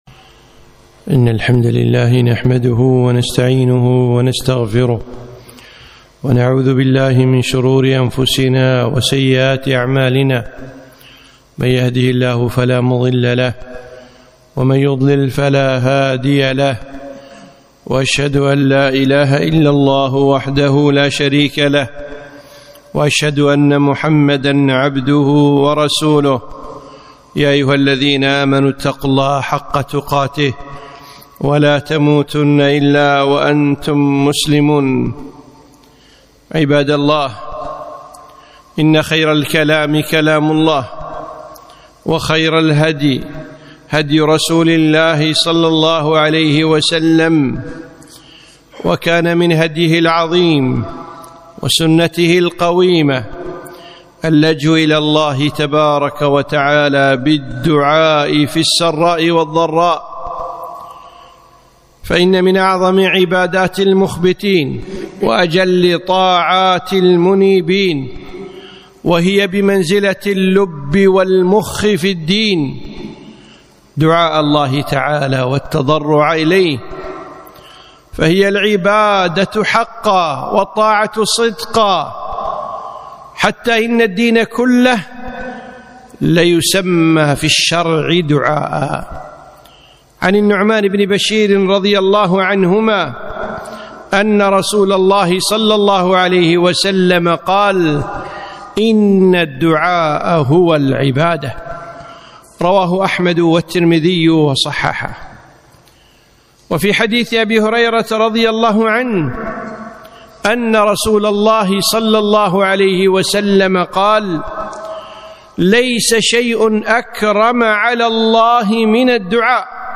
خطبة - ألحوا بالدعاء في هذه الظروف